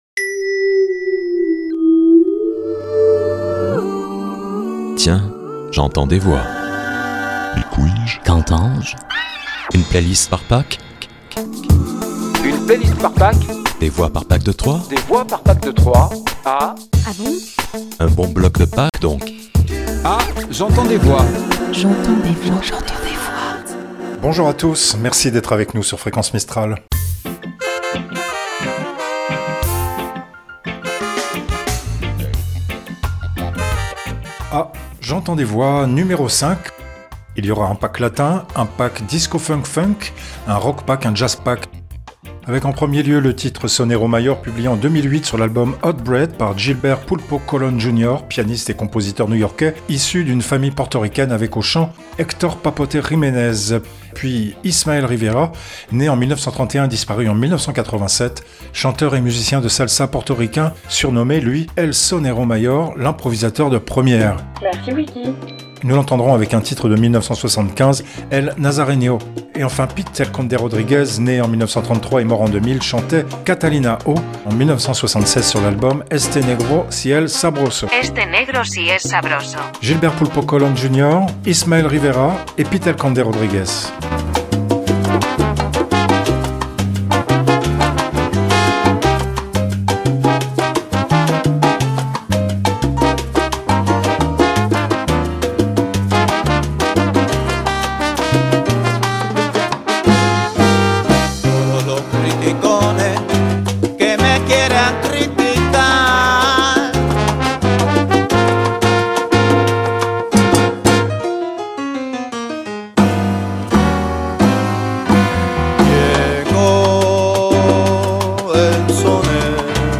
ROCKPACK
JAZZPACK
Générique, jingles, voix additionnelles
Fonds sonores, générique, jingles